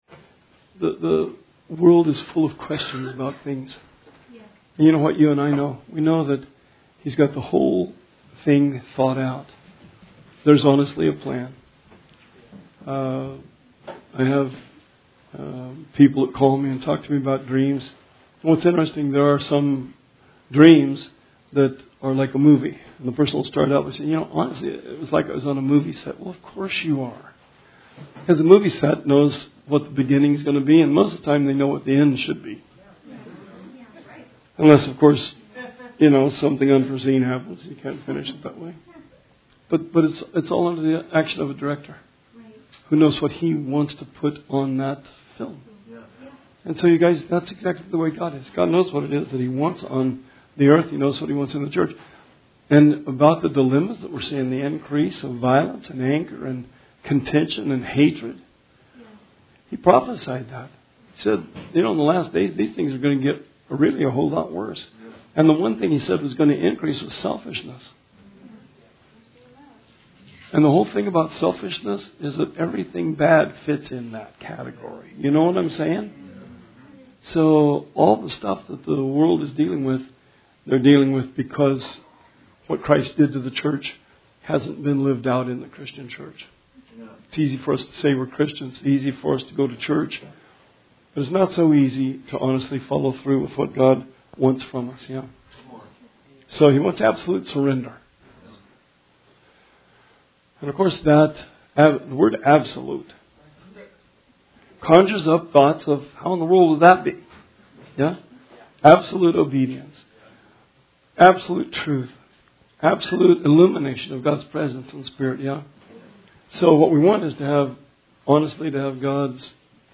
Bible Study 10/30/19